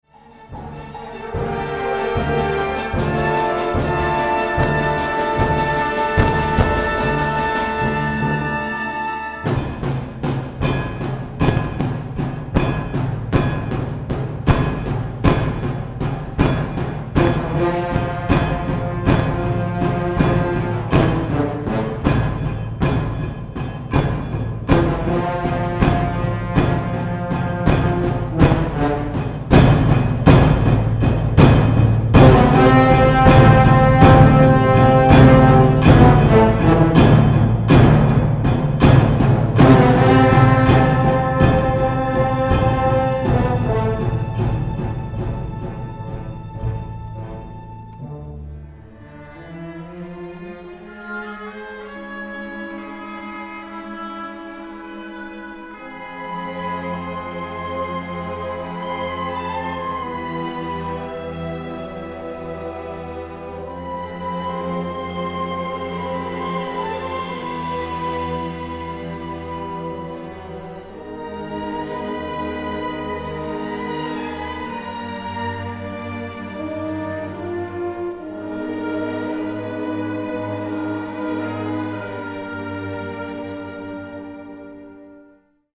LugarClub Campestre